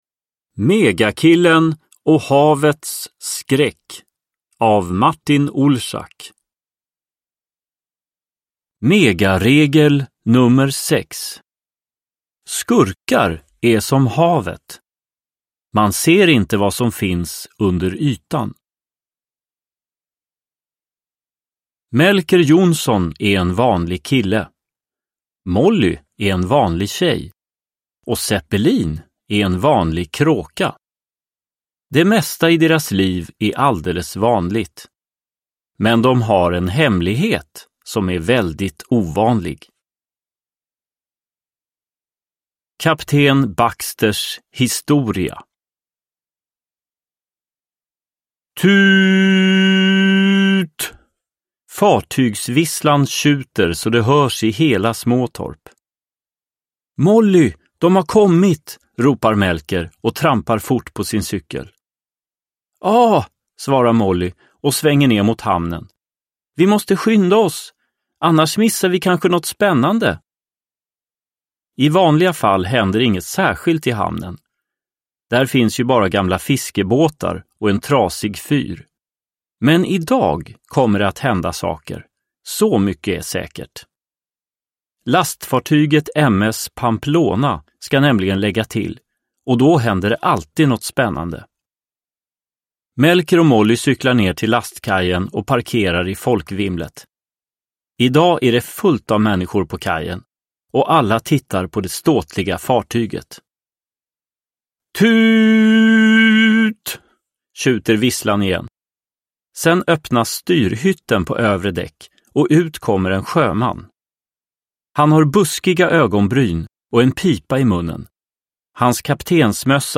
Megakillen och havets skräck – Ljudbok – Laddas ner